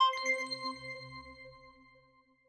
Button_6_Pack2.wav